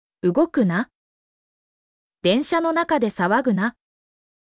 mp3-output-ttsfreedotcom-4_luVBFWqb.mp3